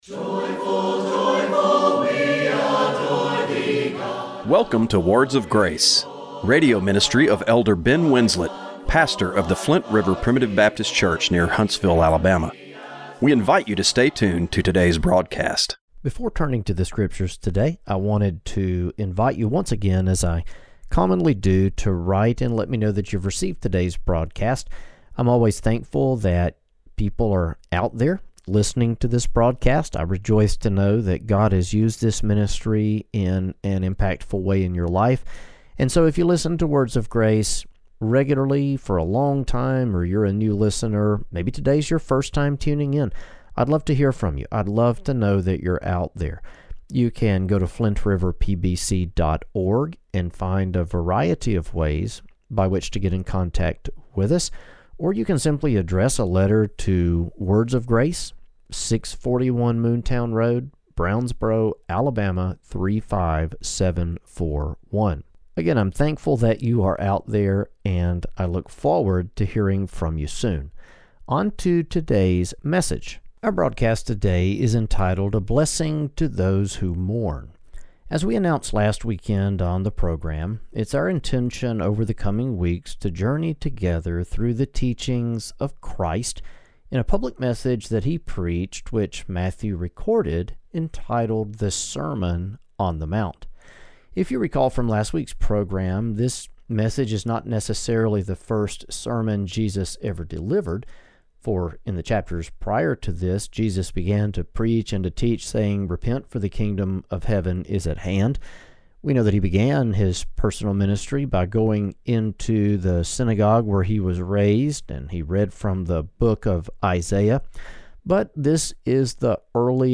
Radio broadcast for February 9, 2025.